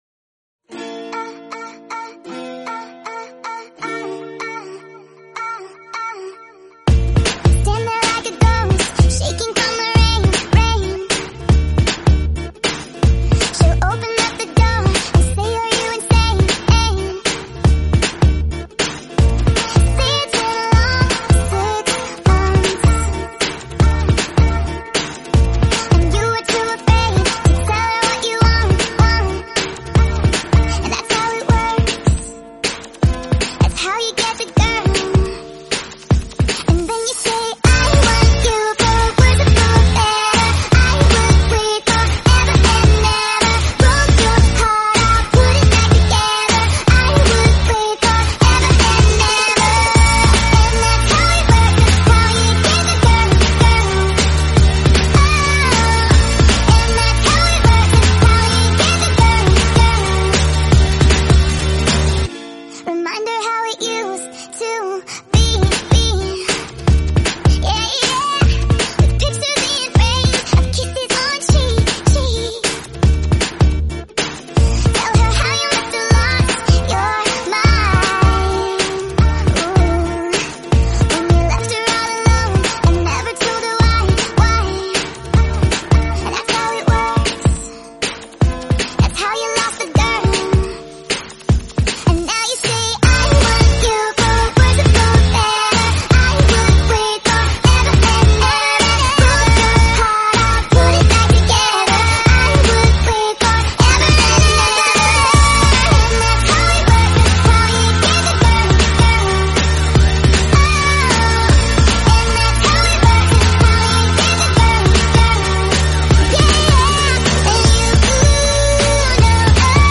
full sped up song